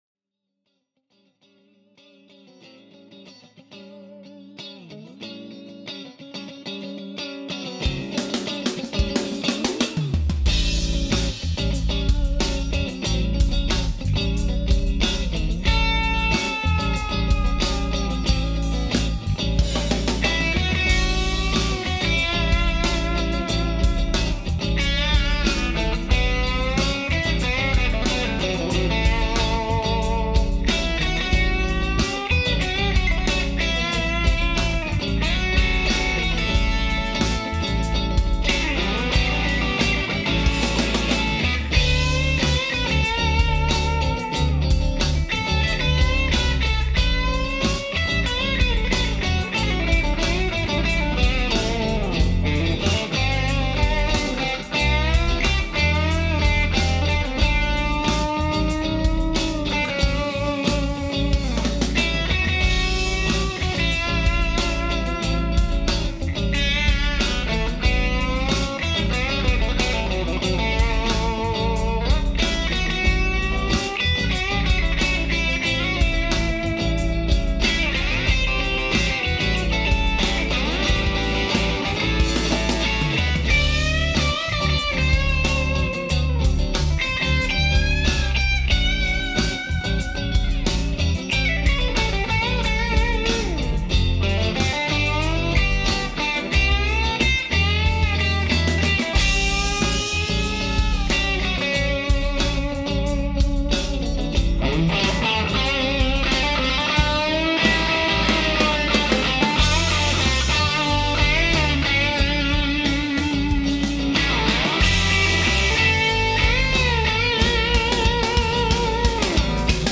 I’ve been working on a new instrumental for over a month now, called “Strutter.”
But it’s different in the studio.
Rhythm: Fender MIM Strat / Aracom VRX22 (6V6) Clean Channel
Lead 1 : Squier Classic Vibe Telecaster 50’s (bridge) / Aracom VRX18 (EL84) Channel 2 (Master cranked / Volume 3pm)
Lead 2 : Saint Guitars Messenger (bridge) / Aracom VRX22 Channel 2 (Master 4pm / Volume 3pm)
All guitars were recorded at bedroom level using the Aracom PRX150-Pro attenuator, with no effects.
Small room reverb was added during production to give a more spatious effect to the lead tracks, and absolutely no EQ was applied to the guitars.
🙂 At least that was the kind of vibe I wanted to capture: 70’s-style guitar-plugged-straight into the amp. It’s a raw kind of tone.